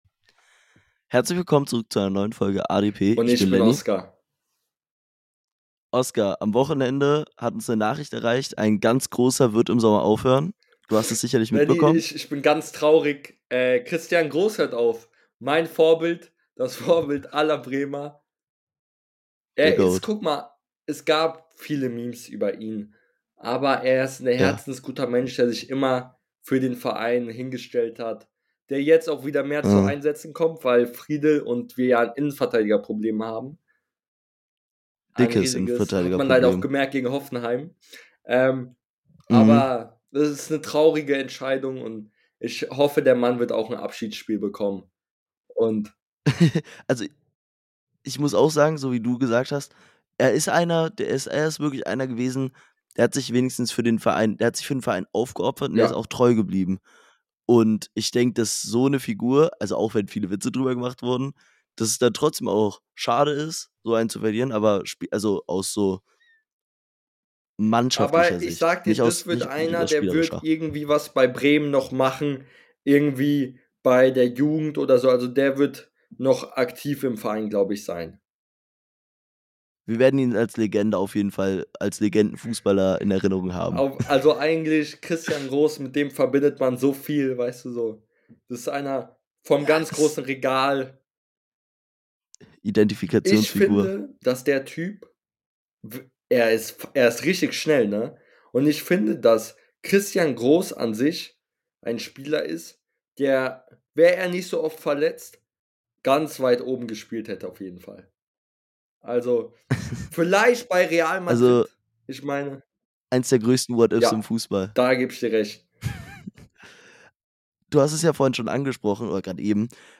In der heutigen Folge reden die beiden Host über das Karriereende von Christian Groß, die Überraschung der Seria A , den Bundesligaspieltag und vieles mehr Jede Woche eine neue Folge